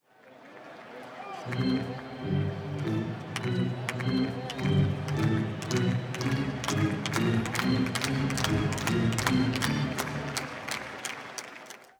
charge-organ.wav